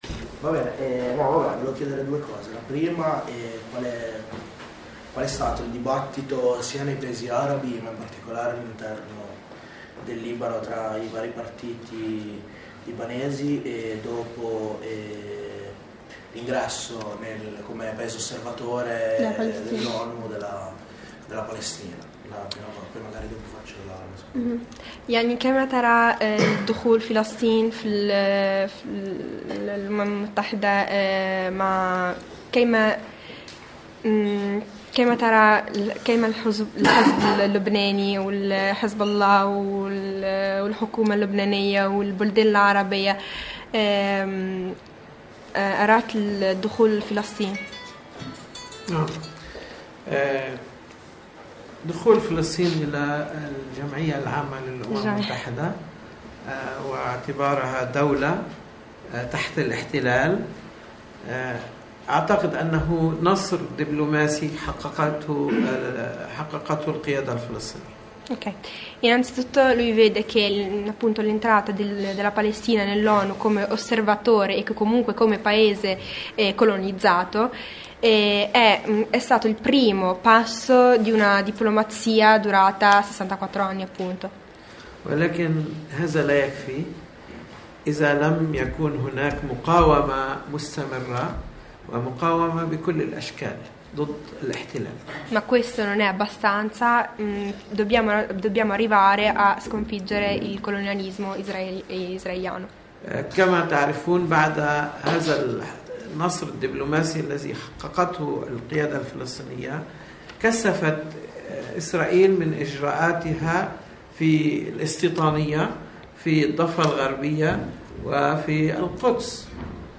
Ascolta la conferenza integrale 1° parte 2° parte